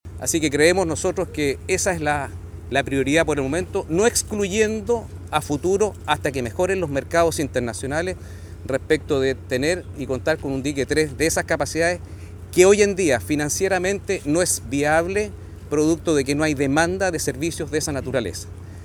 cua-dique-1-comandante.mp3